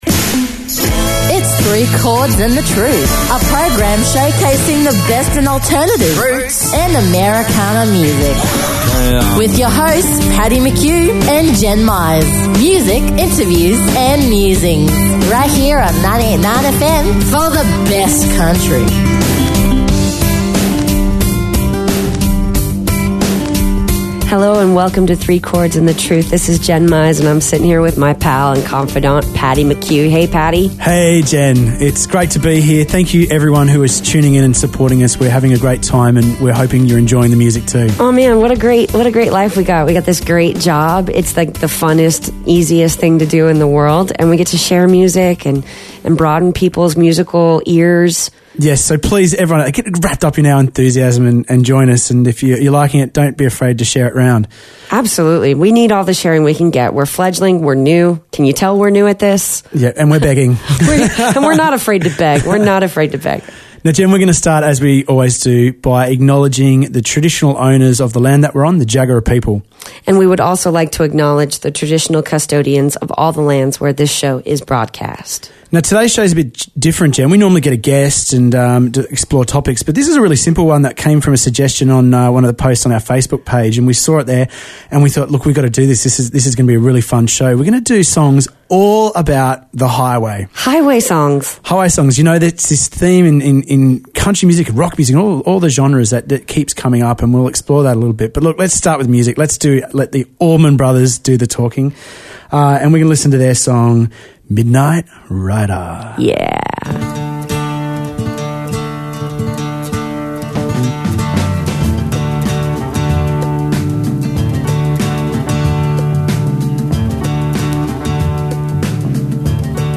Music, interviews and musings every Thursday nights from 6pm and the repeat from 10am Sunday morning.